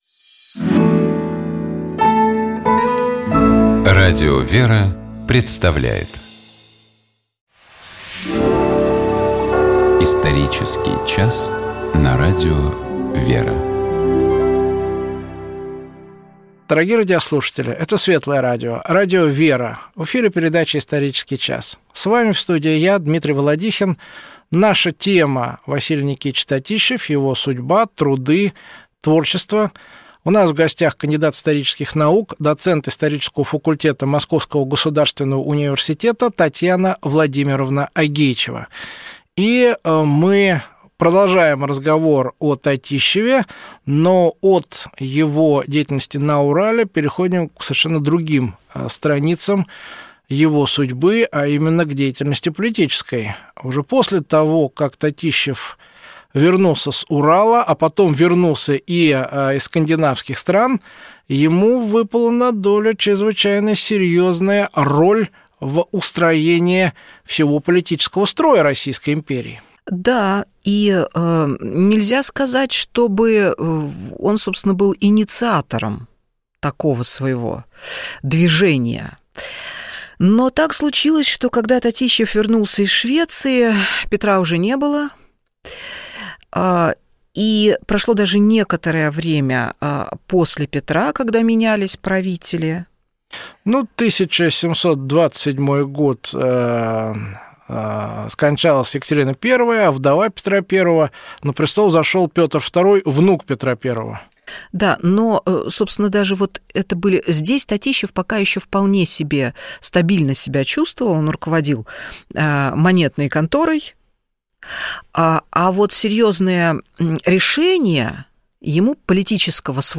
Гость программы: кандидат исторических наук